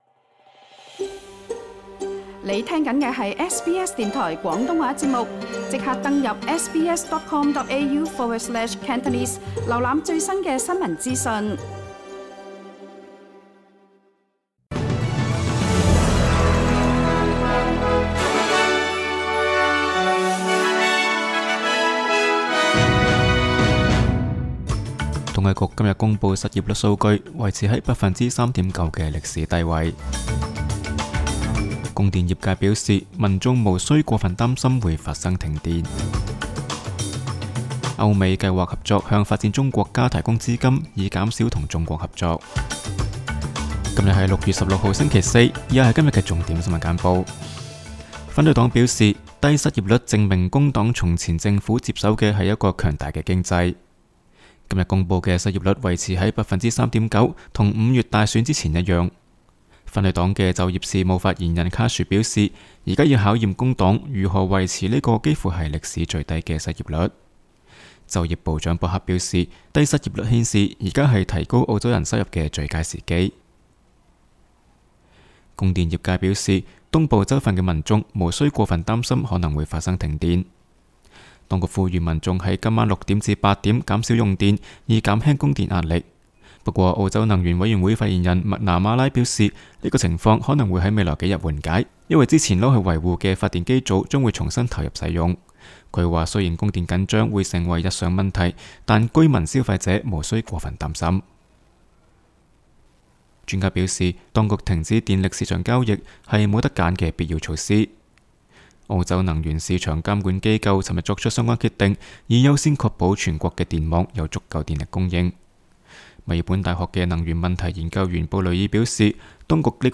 SBS 新闻简报（6月16日）
SBS 廣東話節目新聞簡報 Source: SBS Cantonese